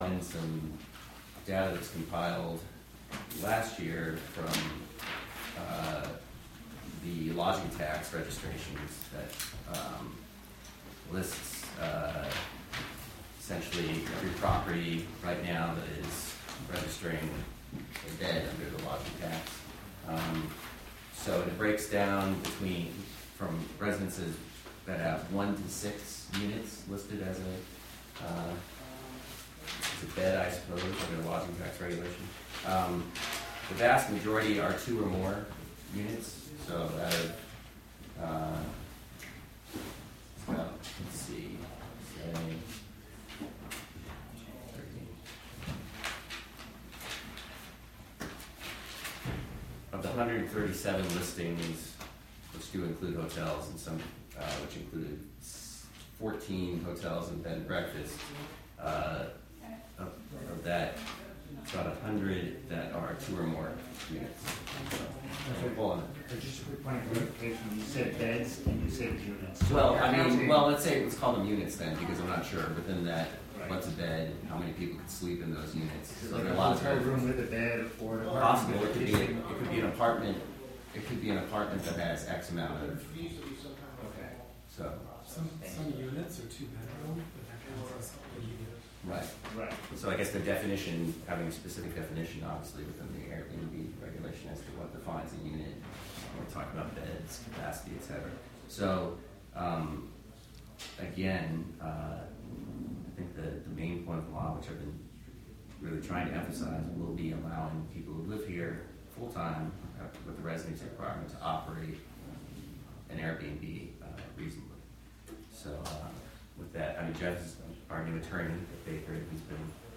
Recorded from a live webstream through a partnership with the City of Hudson and the Wave Farm Radio app and WGXC.